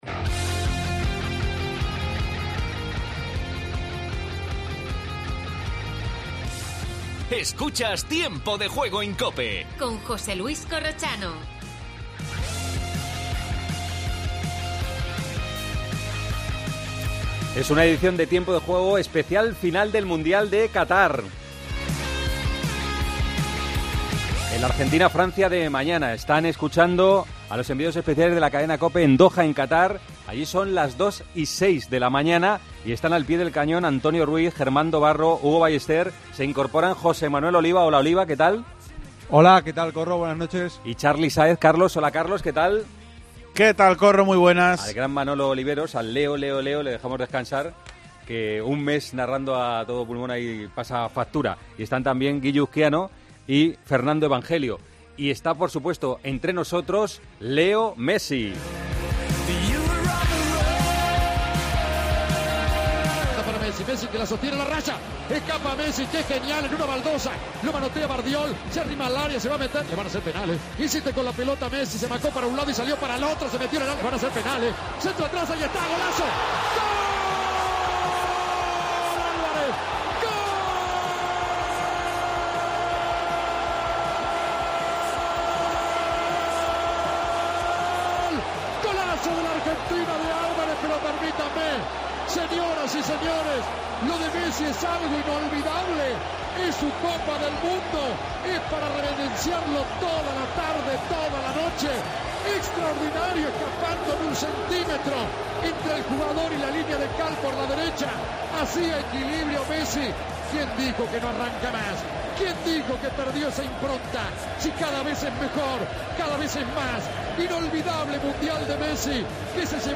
Entrevista a La Mosca, cantante del himno de la afición argentina en el Mundial...